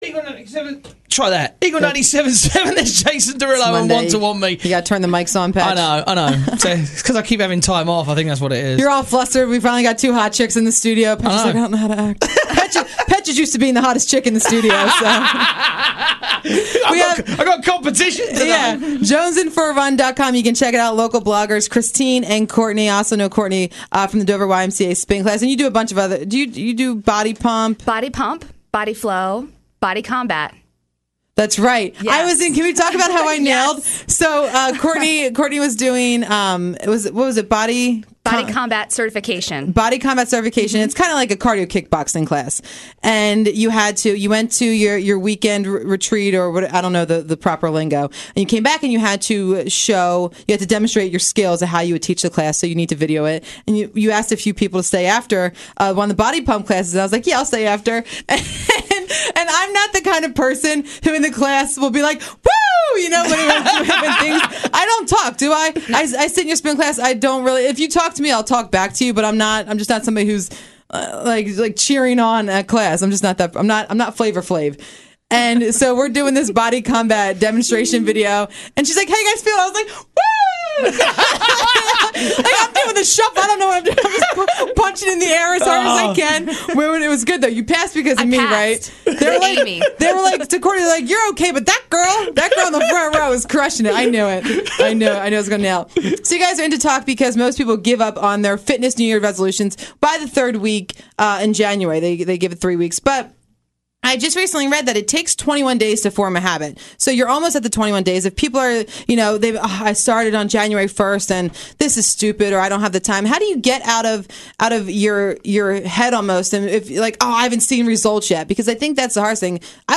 came on air to talk about how to stick to those healthy goals.